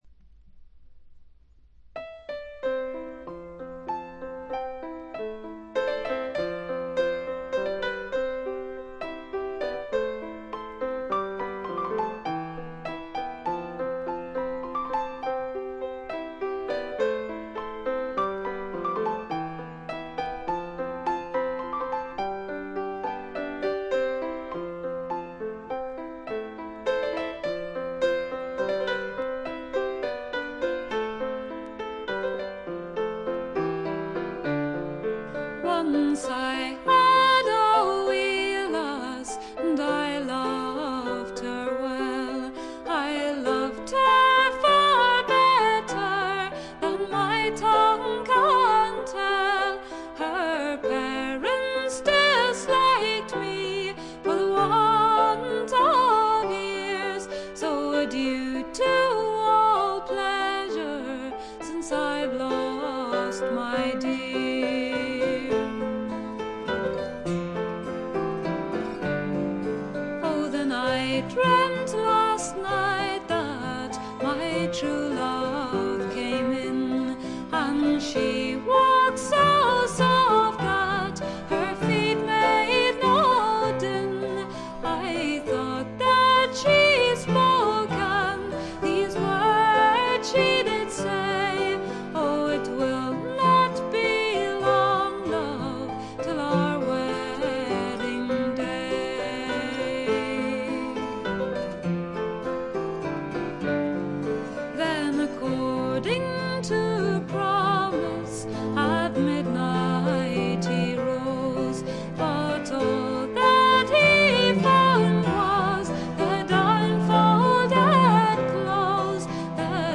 ほとんどノイズ感無し。
アイルランドの女性シンガー
天性のとても美しい声の持ち主であるとともに、歌唱力がまた素晴らしいので、神々しいまでの世界を構築しています。
試聴曲は現品からの取り込み音源です。
Cello
Low Whistle
Vocals, Piano, Acoustic Guitar